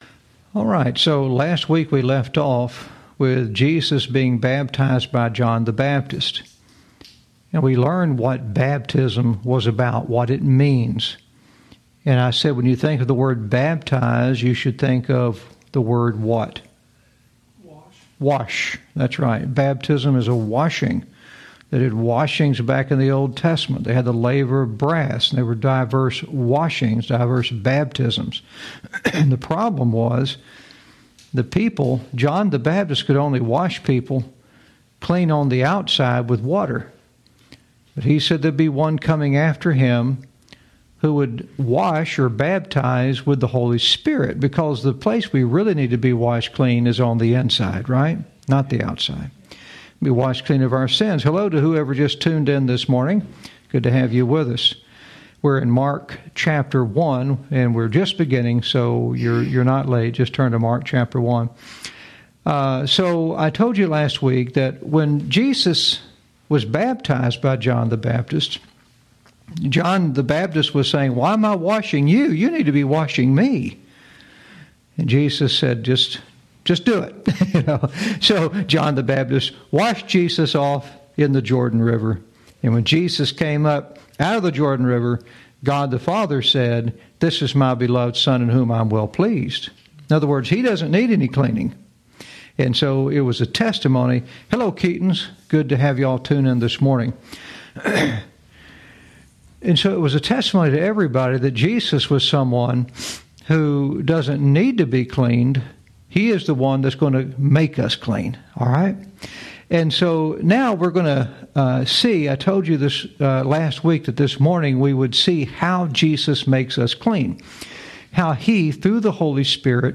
Lesson 33